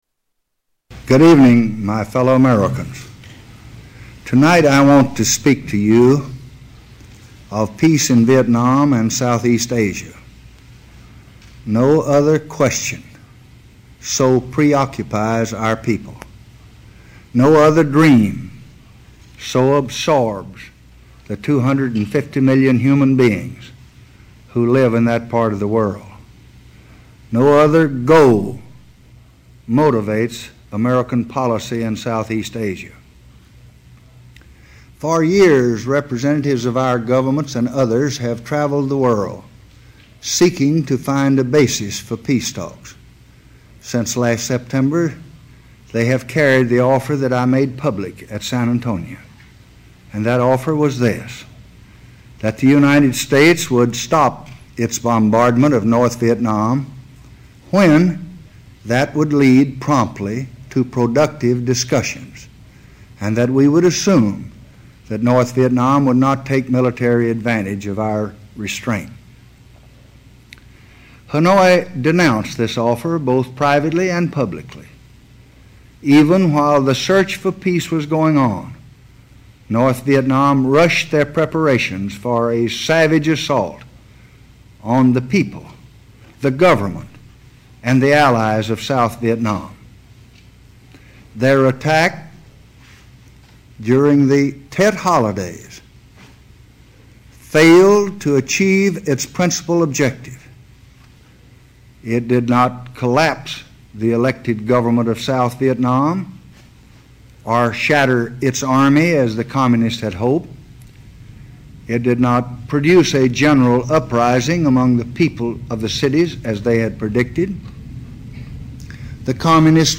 Renunciation Speech Part 1
Tags: Historical Lyndon Baines Johnson Lyndon Baines Johnson clips LBJ Renunciation speech